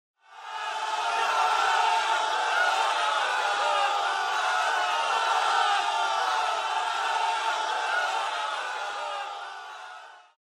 Tm8_Chant24.mp3